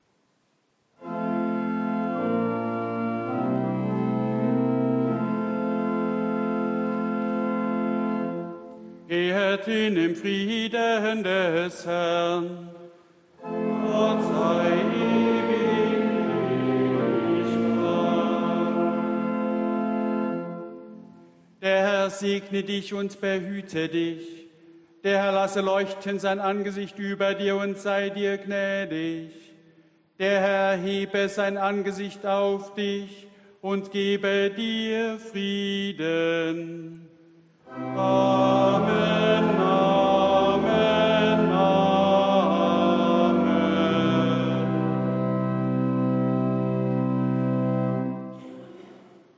Gottesdienst am 12.12.2021
Diesen Gottesdienst hat die Jugend unserer Gemeinde maßgeblich mit vorbereitet und ausgestaltet.